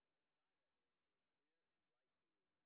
sp10_street_snr0.wav